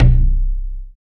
20 LOG DRM-R.wav